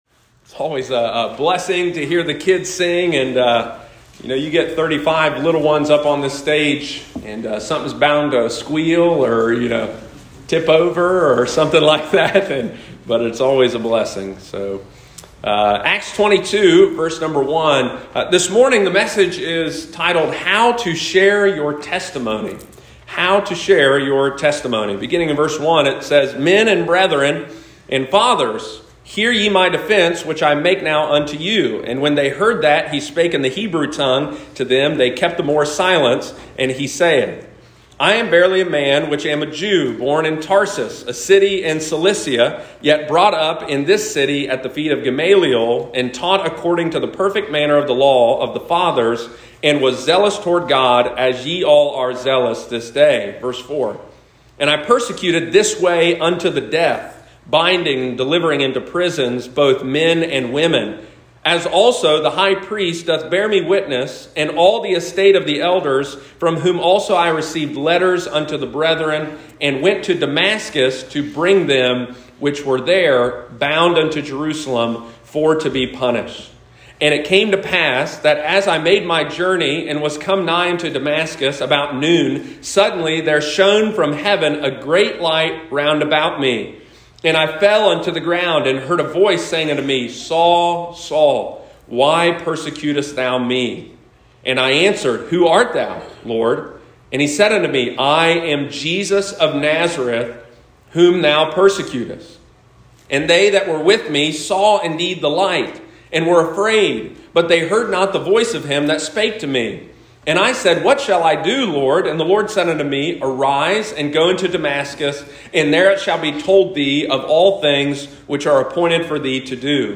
How to Share Your Testimony – Lighthouse Baptist Church, Circleville Ohio
In our text today, the Apostle Paul shows us a pattern for how to share our salvation testimony. Sunday morning, December 11, 2022.